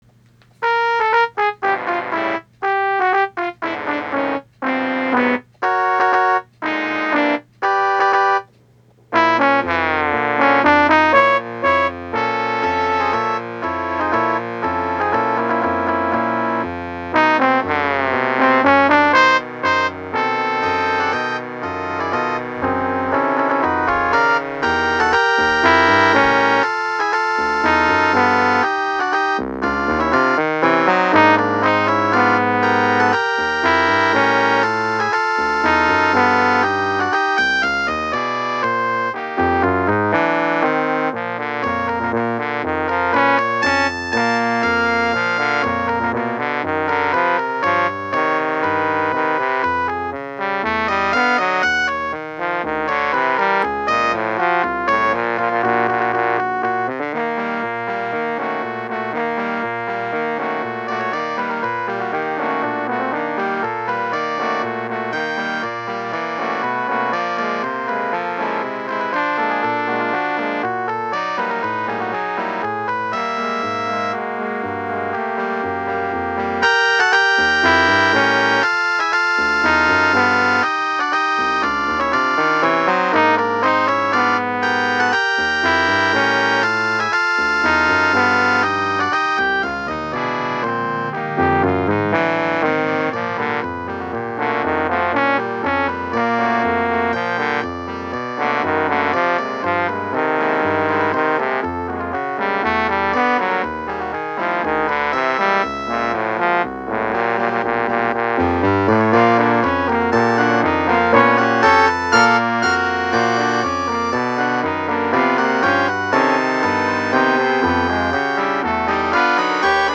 A Day at the Friary, for concert band, written for the Friary Guildford Band.